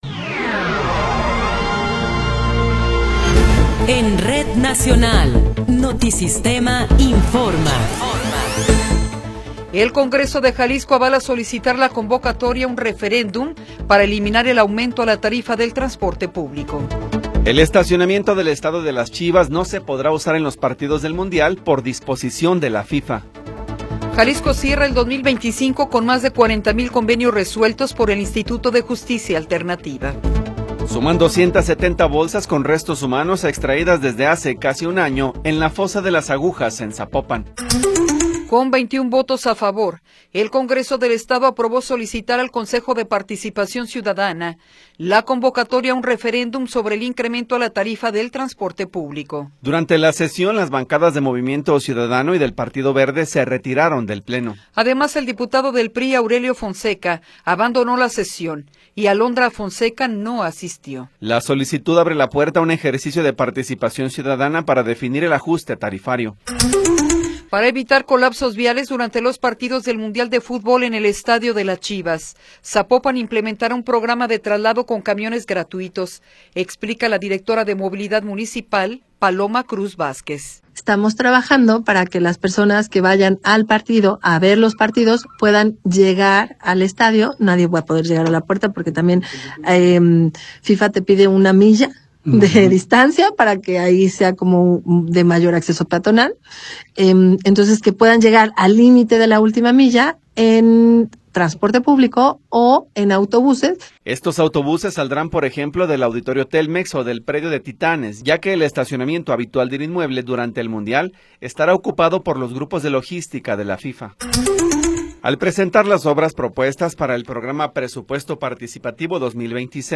Noticiero 14 hrs. – 15 de Enero de 2026